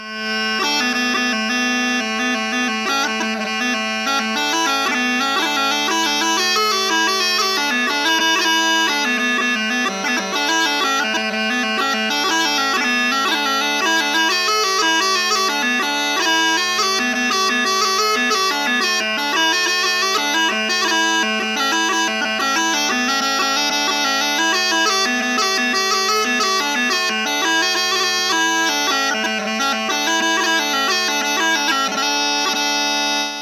Category: Jig Tag: 6/8